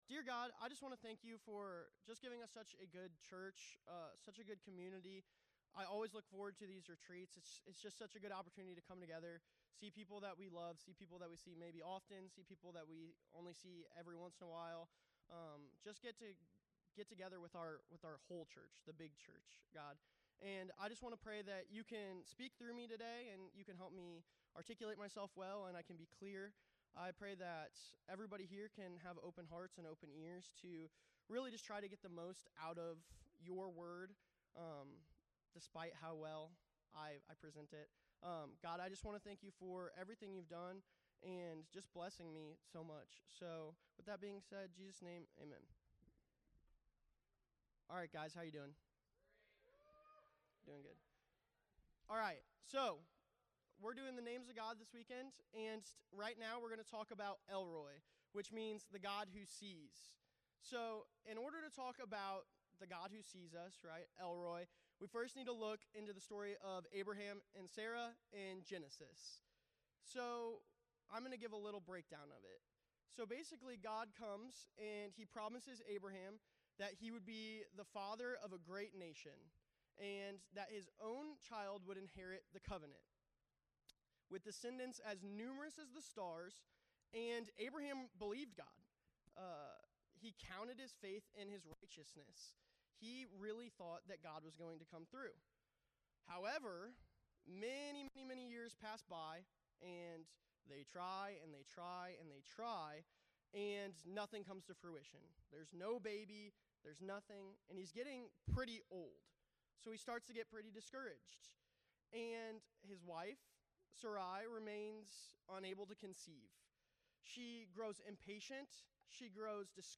Series: "I AM" Refresh Retreat 2026